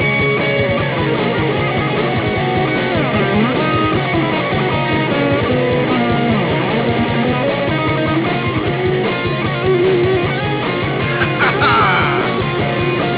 These riffs were recorded in mono to minimize file size.
A guitar jam from a song I wrote called